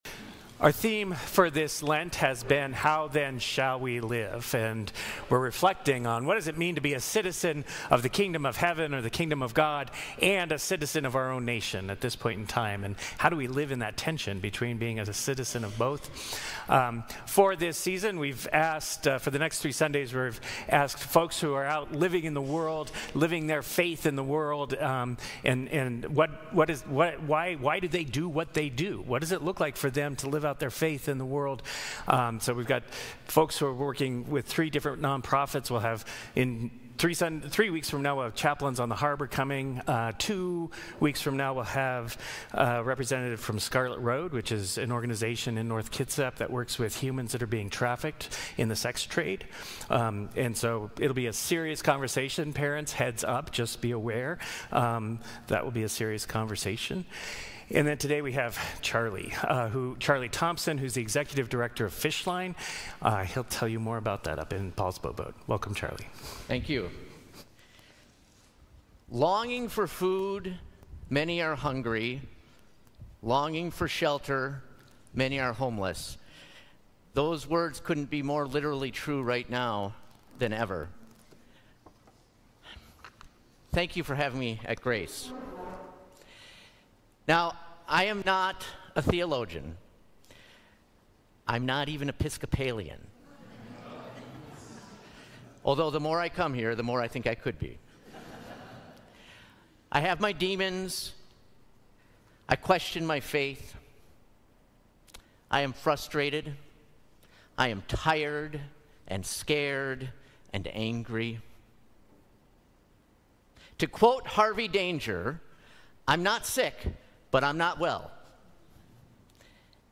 Sermons | Grace Episcopal Church
Guest Speaker